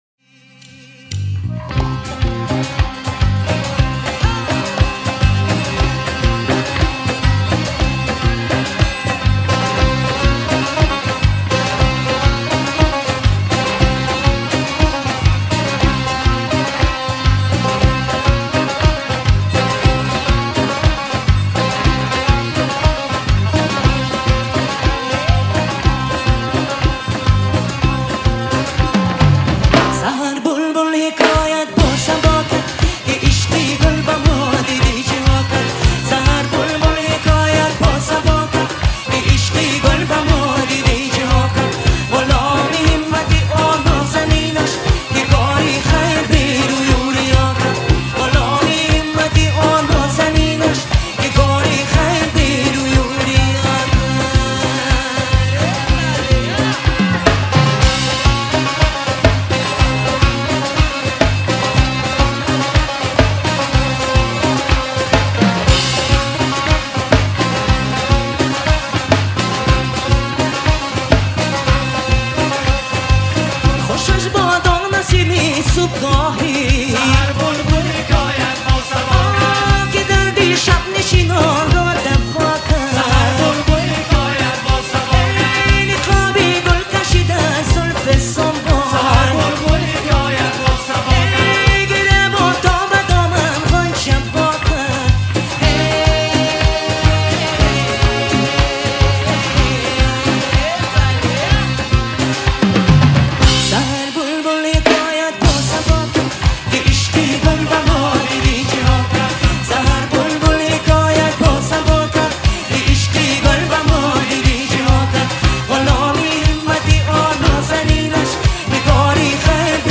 Музыка / 2023- Год / Таджикские / Прочее